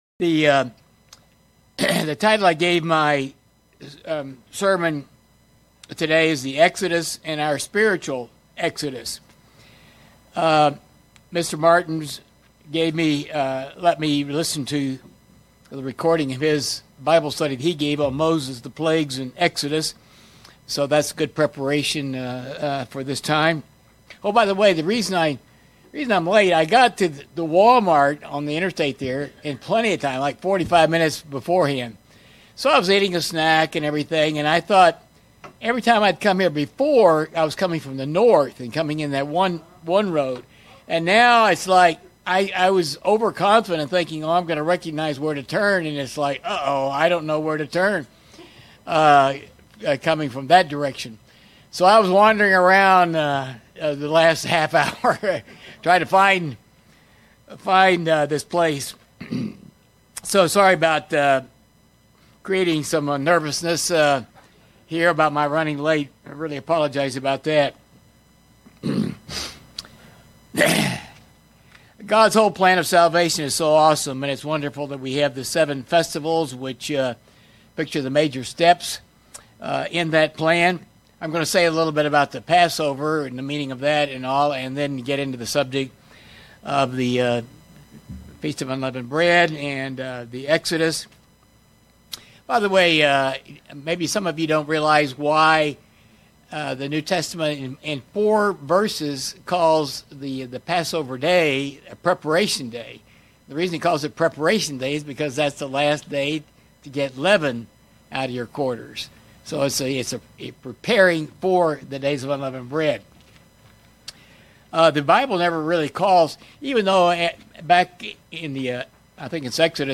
Sermons
Given in Springfield, MO